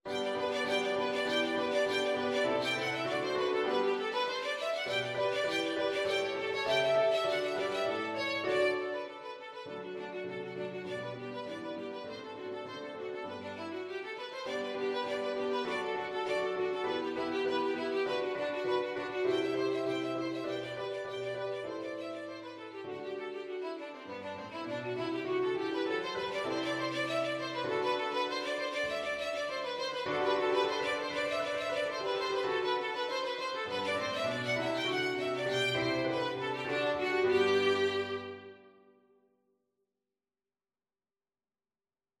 Violin
4/4 (View more 4/4 Music)
G major (Sounding Pitch) (View more G major Music for Violin )
=200 Allegro (View more music marked Allegro)
Classical (View more Classical Violin Music)